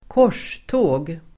korståg substantiv (bildligt " stor kampanj"), crusade [figuratively, " reforming campaign undertaken with zeal"]Uttal: [²k'år_s:tå:g] Definition: medeltida kristna krigståg för att befria Jerusalem från muslimerna